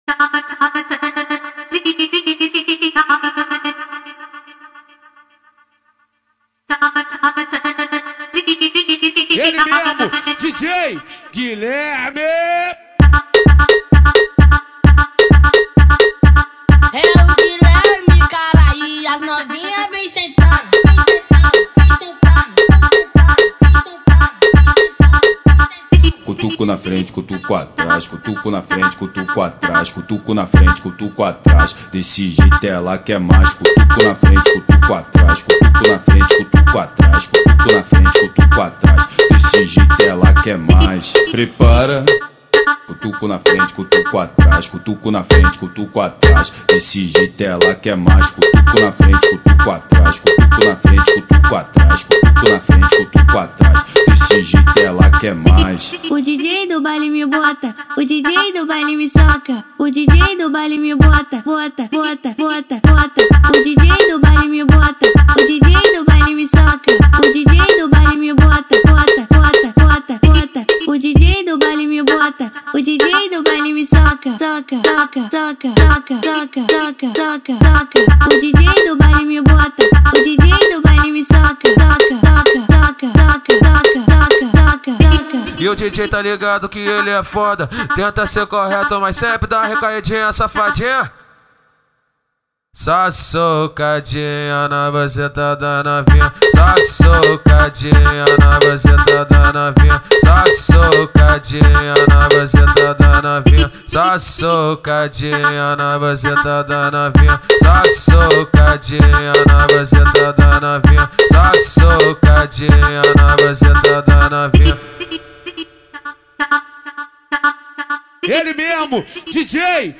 Индийский фонк с крутыми переходами
Басами и прочими, звуковыми фишками.